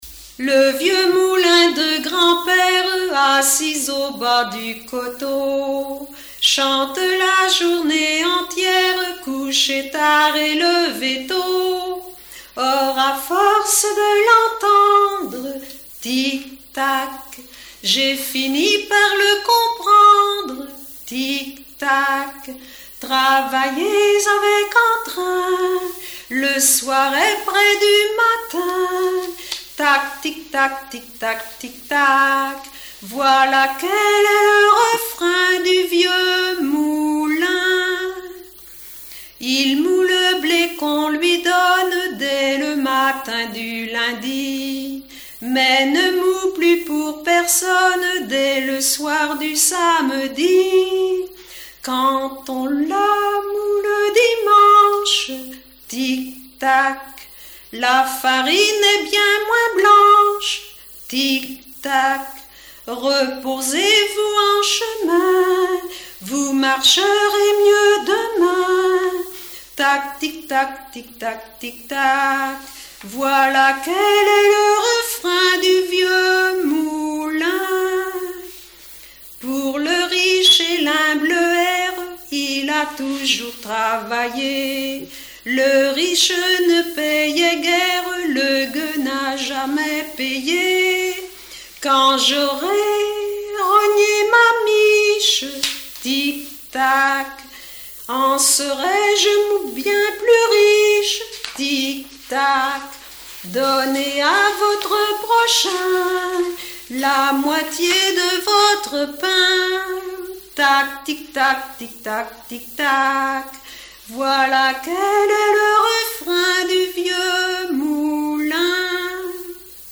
Répertoire de chansons populaires et traditionnelles
Pièce musicale inédite